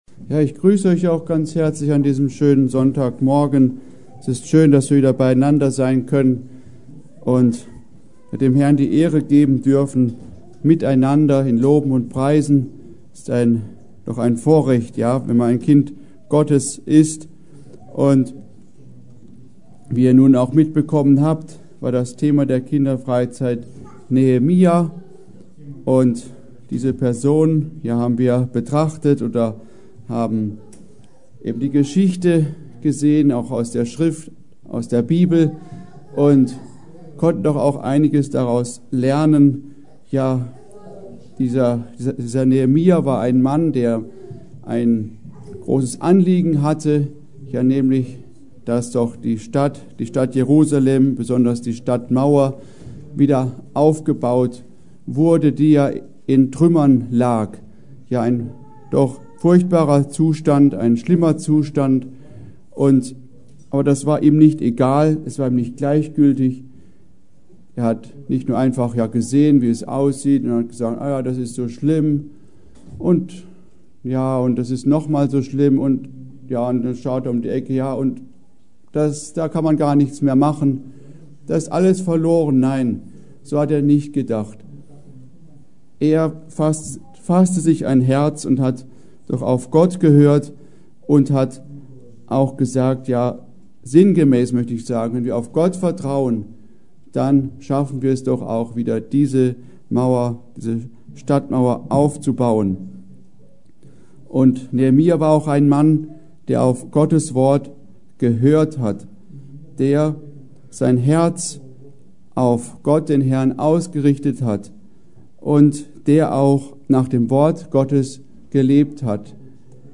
Predigt: Ausrichtung des Herzens auf Gottes Wort